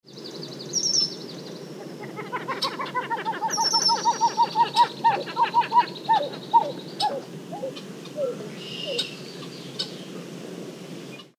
The pied-billed grebes’ call has been rendered thus: a “whooping kuk-kuk-cow-cow-cow-cowp-cowp.”